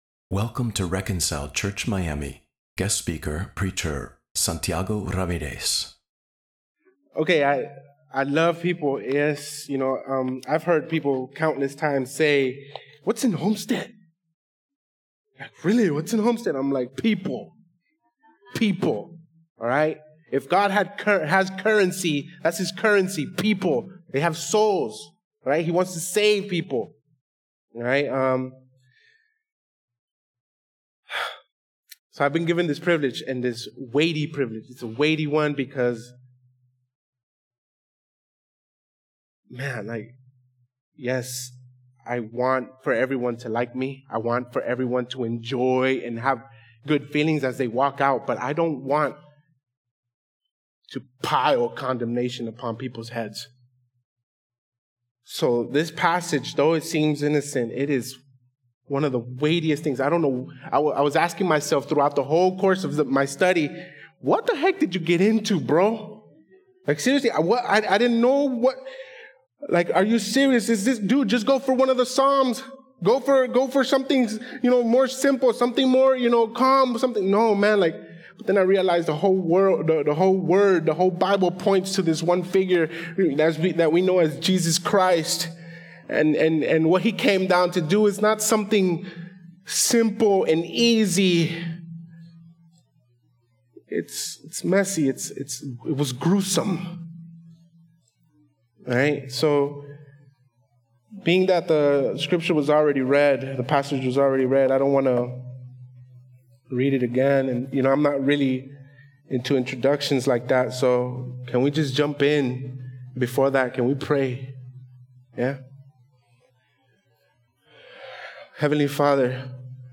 John 3:1-15 Independent Sermons Delivered on